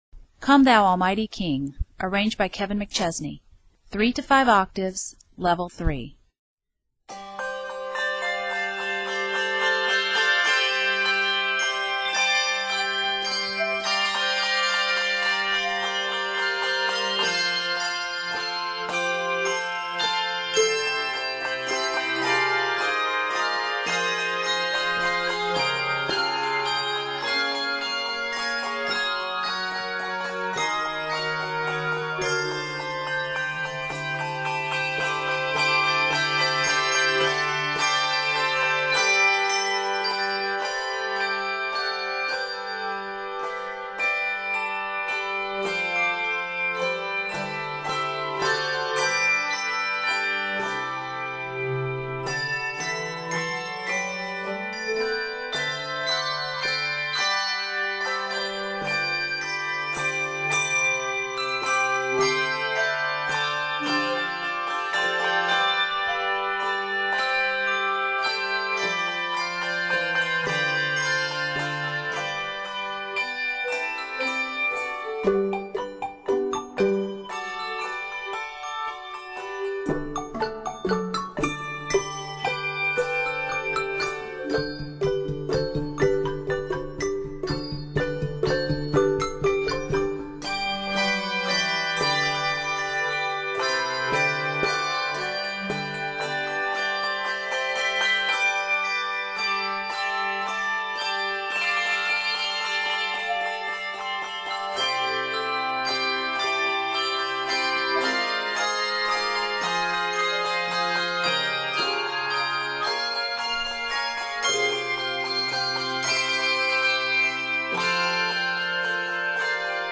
is scored in C Major, G Major, and F Major